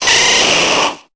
Cri de Florizarre dans Pokémon Épée et Bouclier.